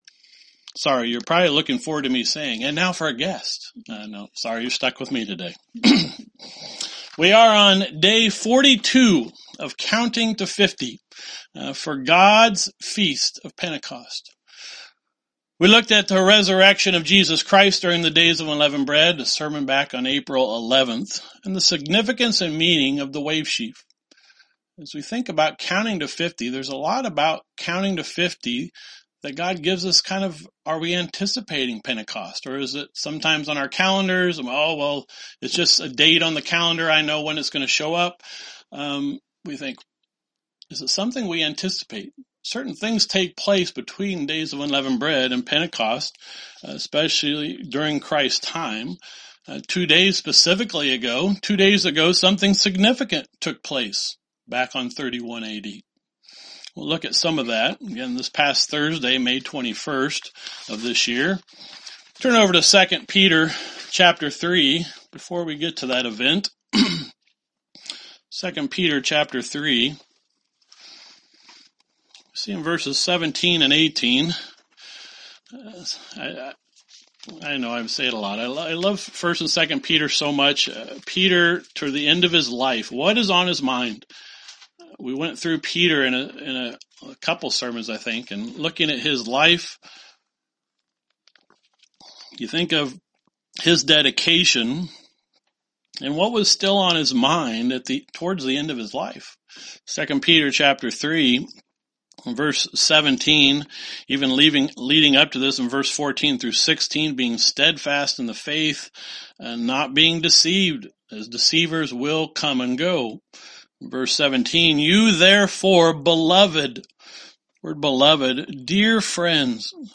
Sermons
Given in Elkhart, IN Northwest Indiana